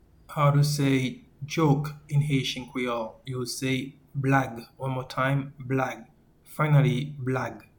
Pronunciation:
Joke-in-Haitian-Creole-Blag.mp3